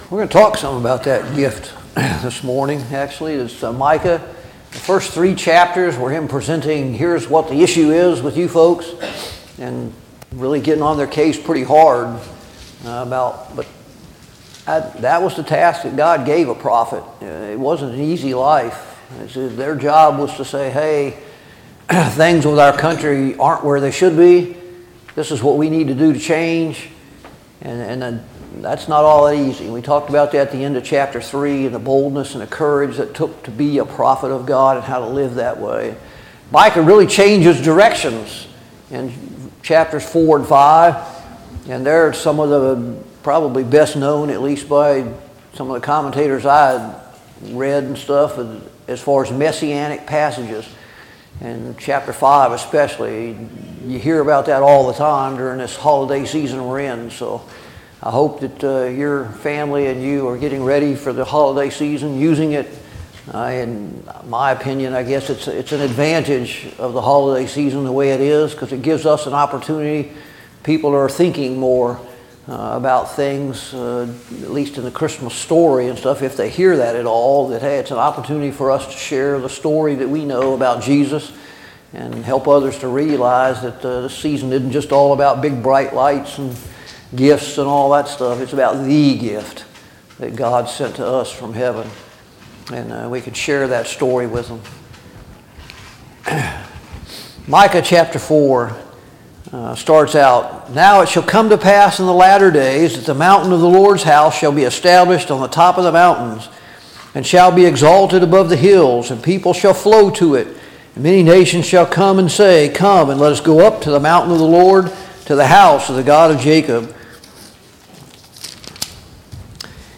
Series: Study on the Minor Prophets Service Type: Sunday Morning Bible Class « 13.